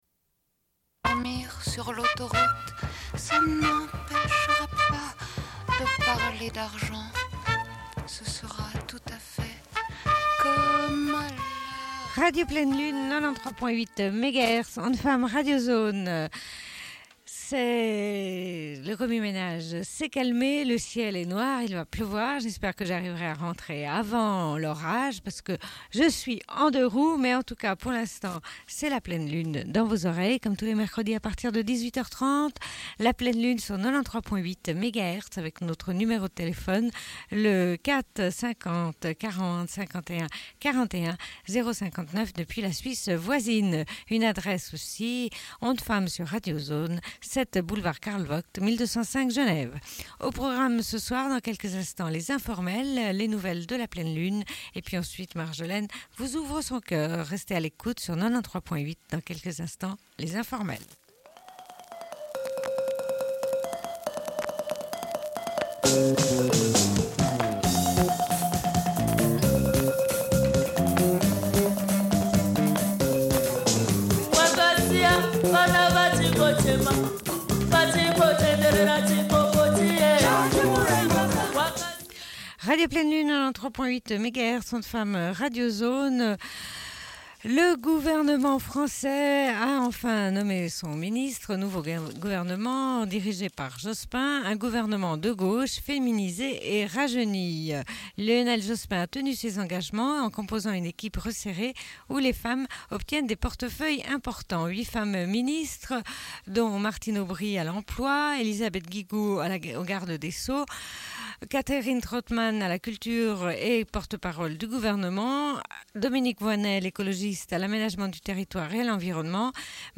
Bulletin d'information de Radio Pleine Lune du 10.11.1993 - Archives contestataires
Une cassette audio, face B31:13